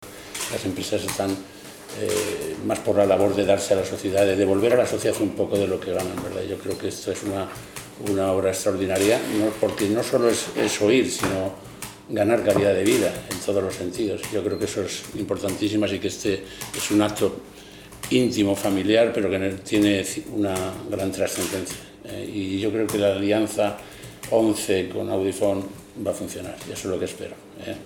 El popular ex seleccionador nacional de fútbol Vicente del Bosque, que es ‘embajador’ de Fundación Audifón, estuvo también presente en el acto y expresó su orgullo por esta iniciativa que -